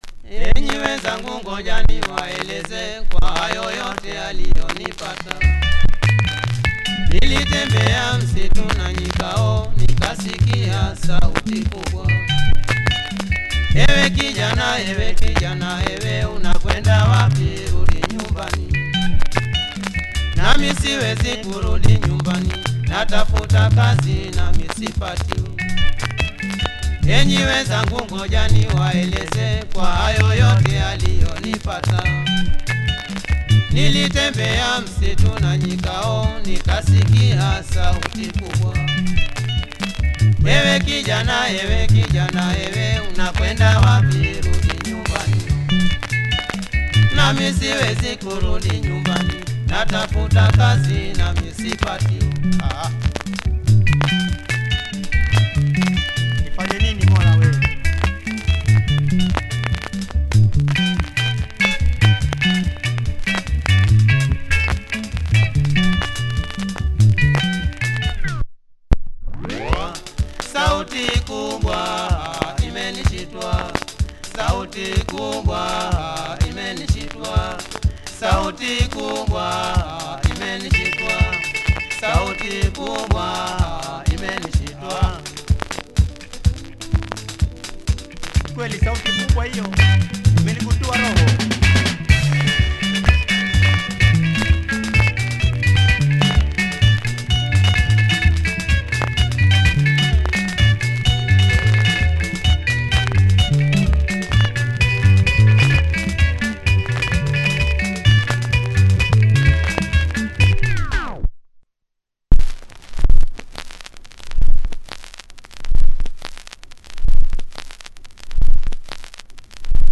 slight warp and plays with some noise. https